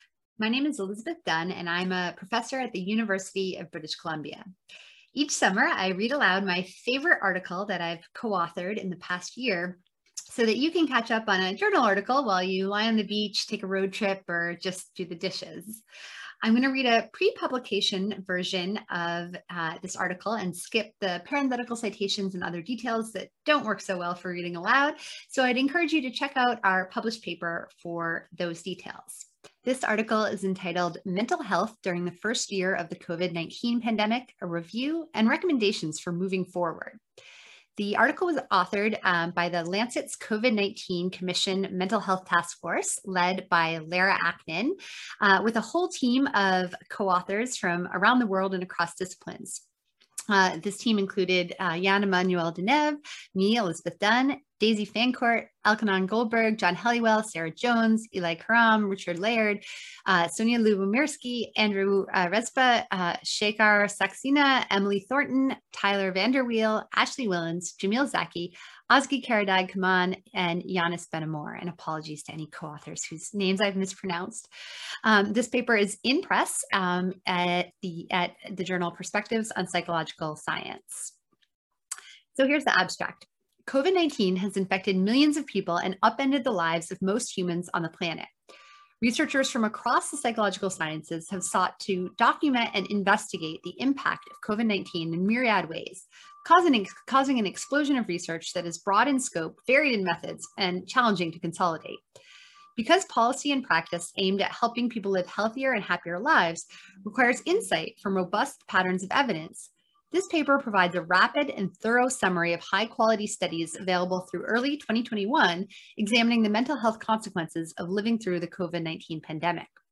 Audio Readings
audio_narration_2021.mp3